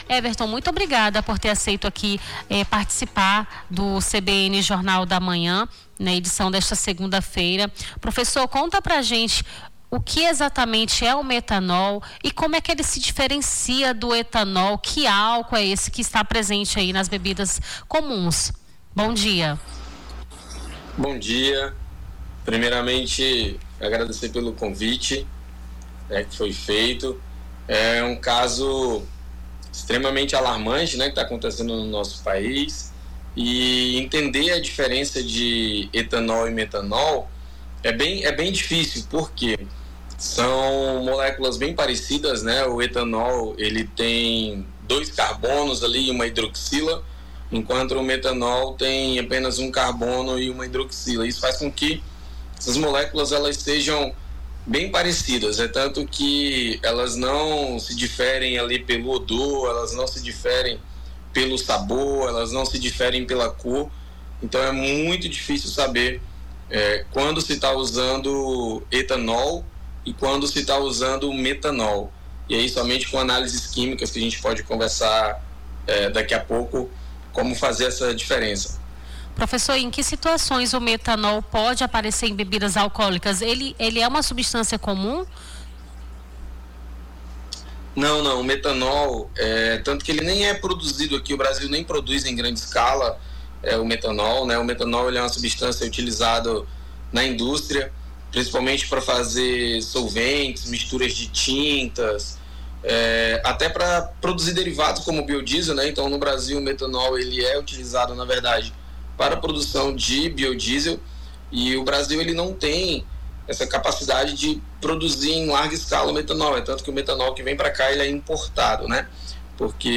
Baixar Esta Trilha Nome do Artista - CENSURA - ENTREVISTA (PERIGO METANOL) 06-10-25.mp3 Foto: Getty Images via BBC Facebook Twitter LinkedIn Whatsapp Whatsapp Tópicos Rio Branco Acre Intoxicação Metanol